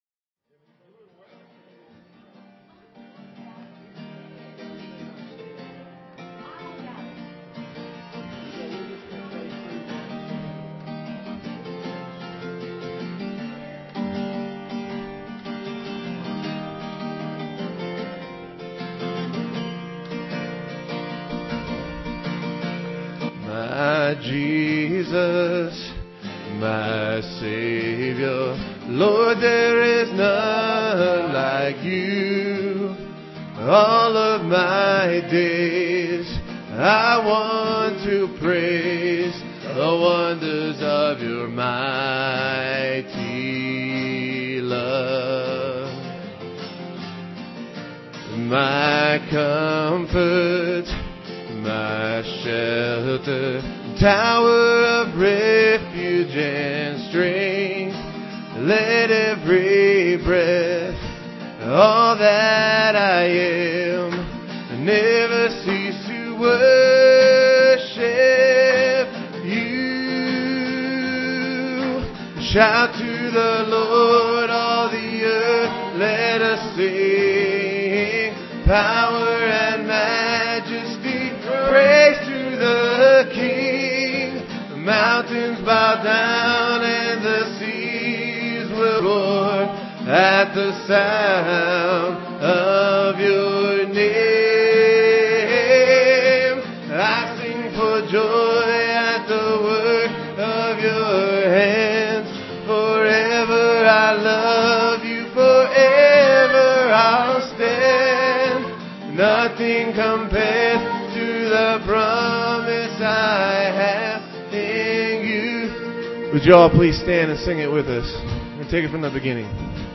Guitars
vocal solos
Piano and organ duet